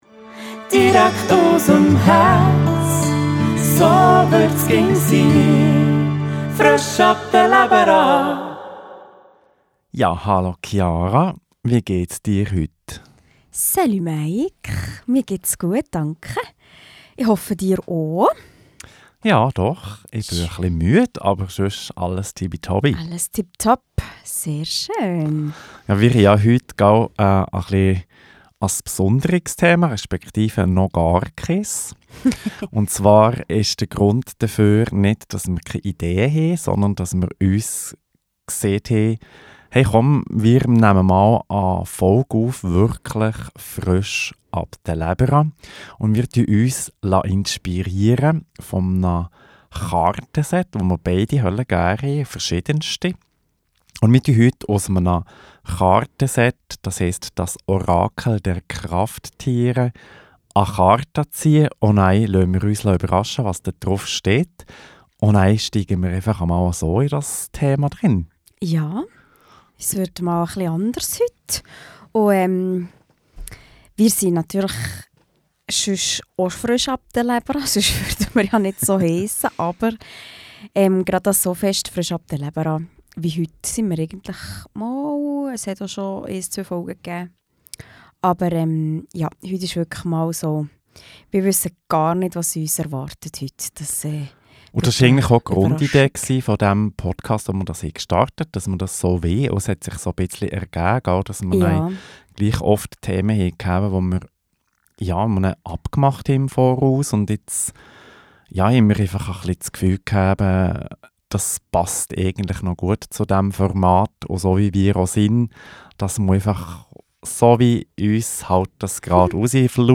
im Gspräch